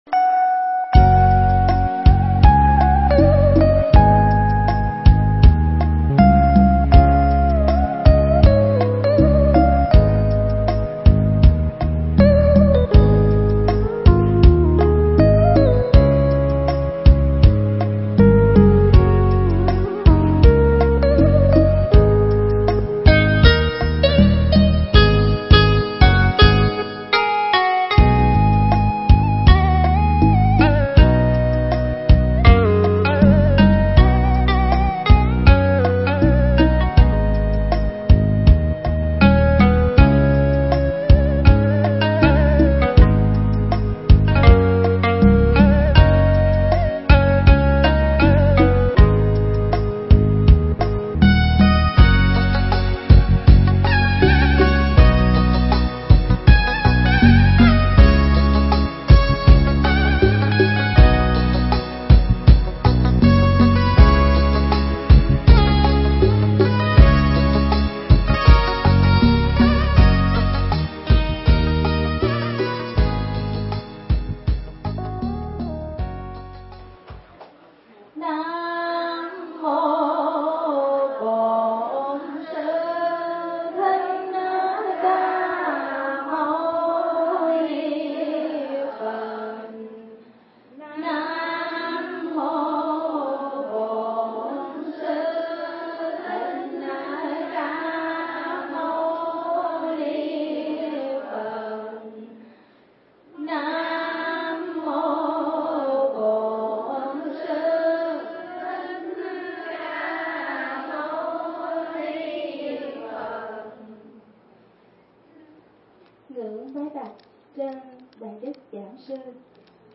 Mp3 Pháp Thoại Quay Đầu Là Bờ